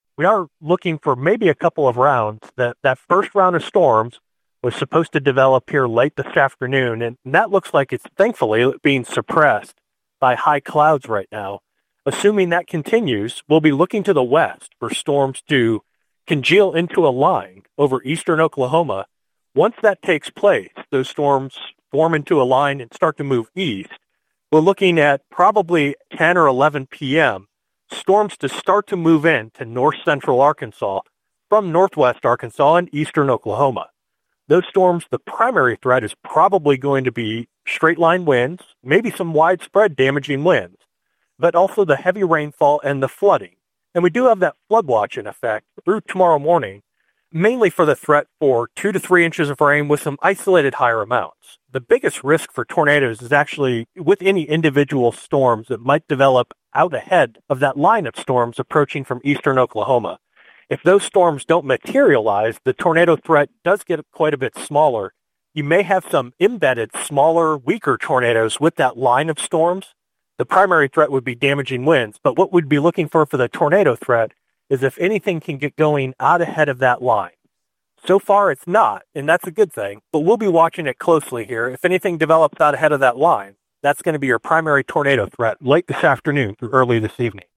KTLO, Classic Hits and The Boot News spoke with National Weather Service (NWS) meteorologist